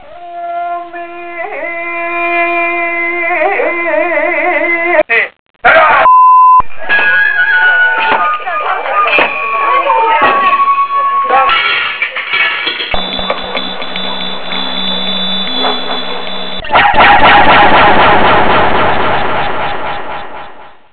江差町の音をカセットテープで録音し、それを左のような波形編集ソフトで編集し、イメージサウンドロゴを作りました。
音素材は江差追分、繁次郎話の語り、お囃子、法華寺の鐘、姥神大神宮の鈴、風鈴などです
soundlogo.wav